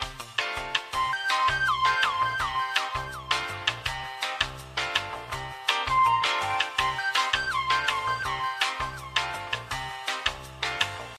Dancing squirrel to elevator music